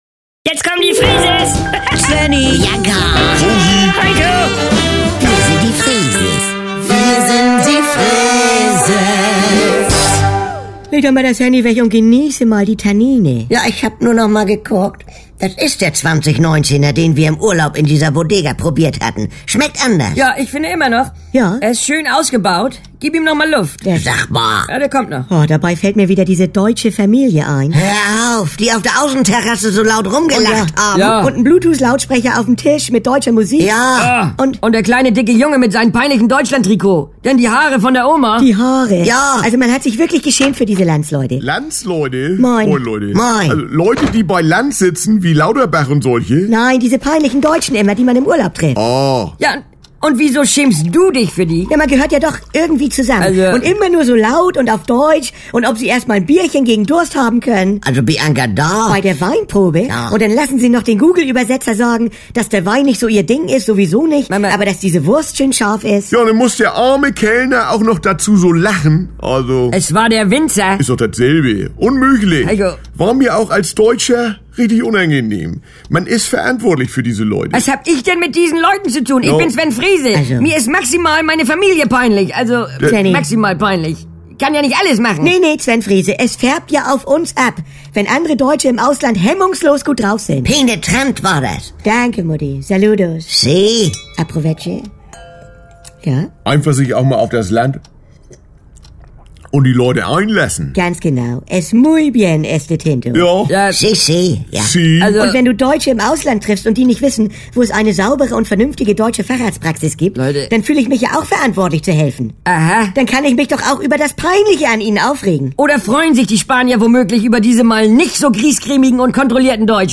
Können wir nicht einmal wie 'ne normale Familie sein?; Hörspiel (3 CDs)